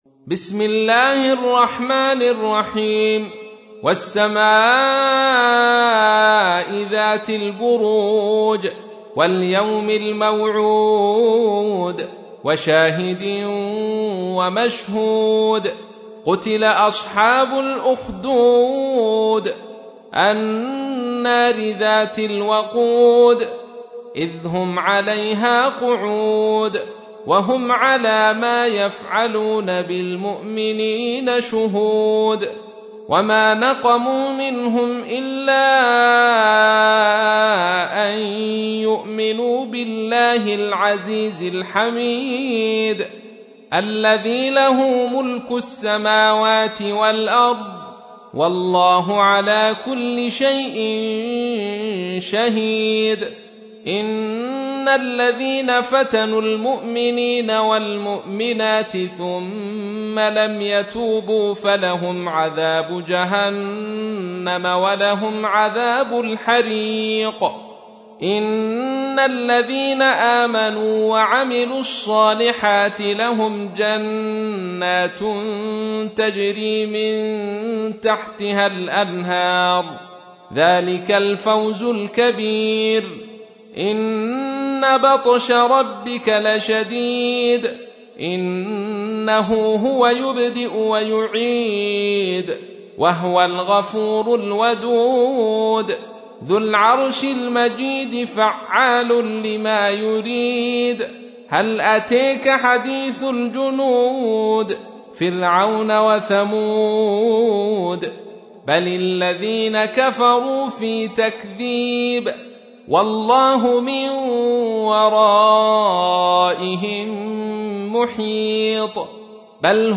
تحميل : 85. سورة البروج / القارئ عبد الرشيد صوفي / القرآن الكريم / موقع يا حسين